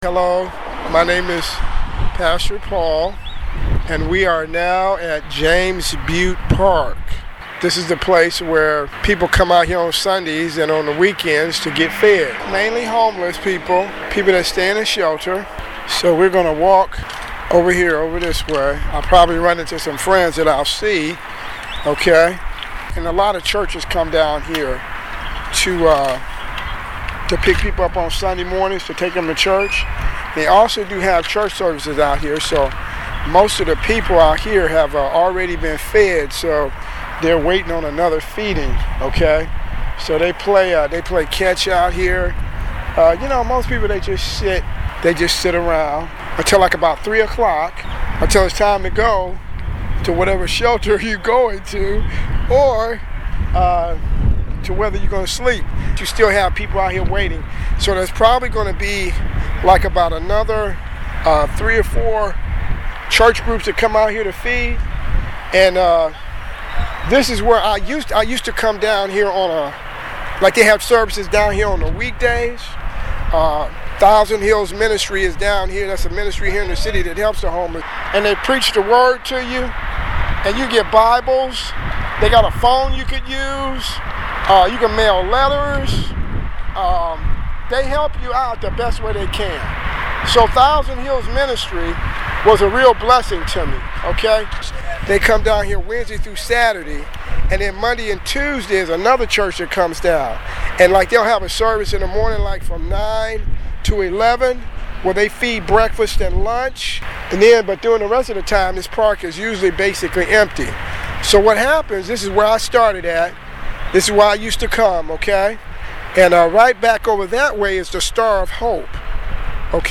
1 mile From James Bute Park to 512 McKee